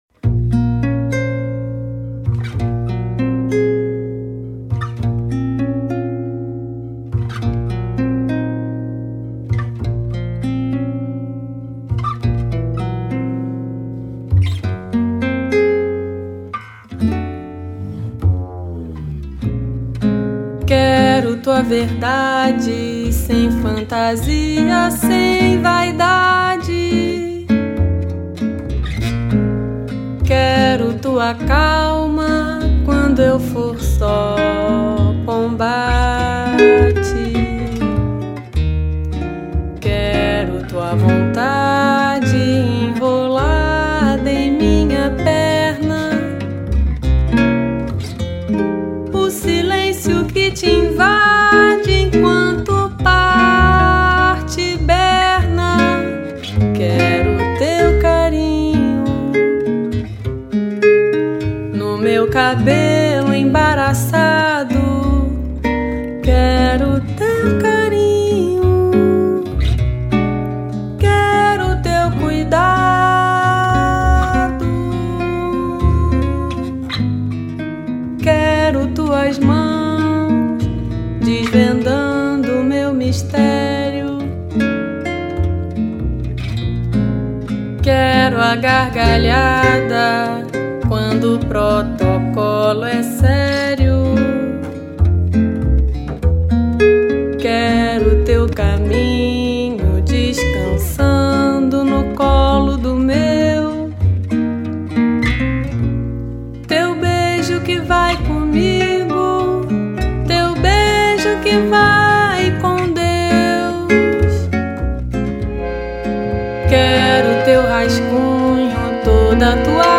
MPB Valsa Canção Lirismo